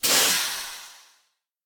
train-breaks-1.ogg